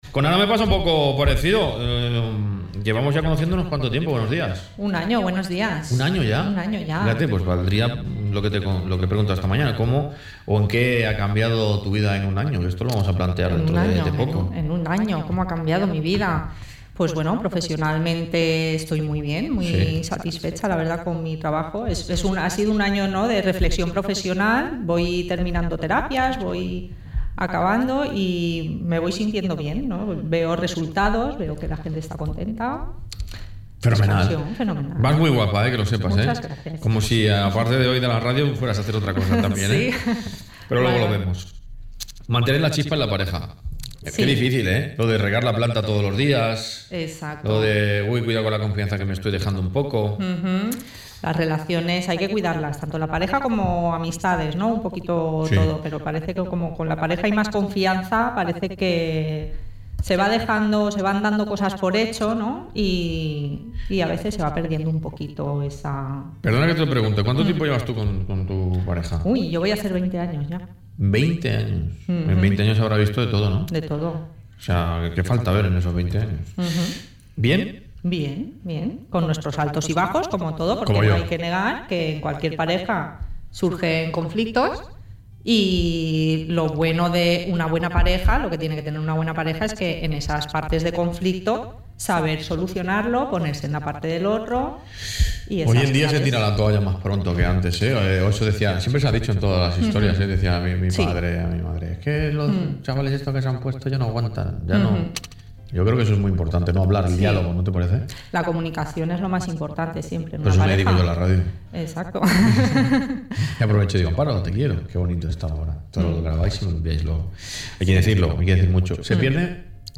El amor tiene algunas fases, las relaciones que son muy duraderas se mantienen dado el compromiso y afecto que se tiene con la persona que decides compartir tu vida, en la entrevista del podcast, puedes escuchar las fases del amor como ideas de mantener la pareja unida. ¿Cómo mantener la chispa en la pareja?